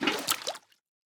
Minecraft Version Minecraft Version latest Latest Release | Latest Snapshot latest / assets / minecraft / sounds / item / bucket / fill_fish3.ogg Compare With Compare With Latest Release | Latest Snapshot
fill_fish3.ogg